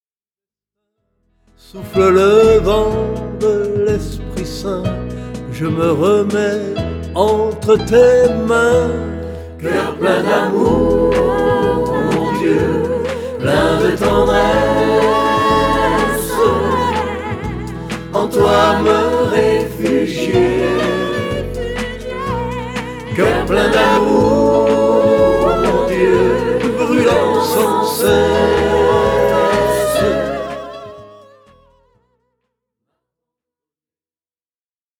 Format :MP3 256Kbps Stéréo